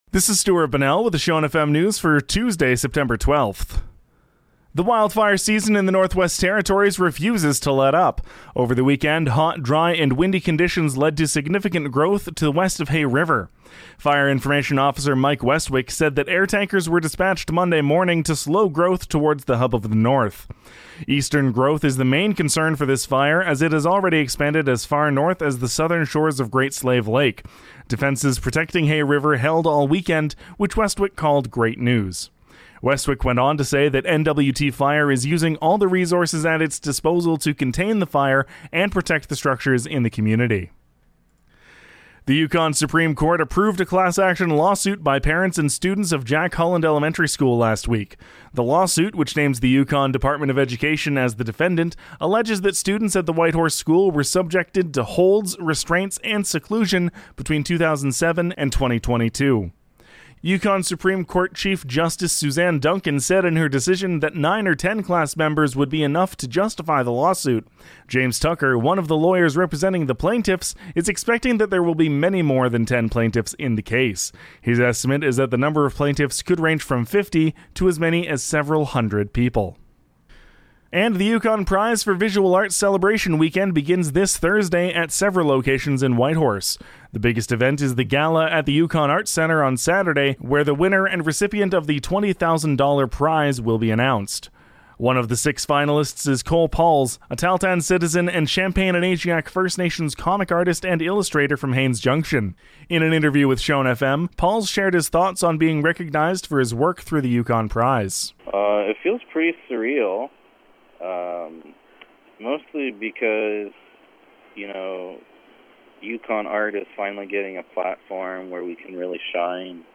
NOTE: The news recording incorrectly states that the Yukon Prize finalists' work is currently on display at the Yukon Arts Centre.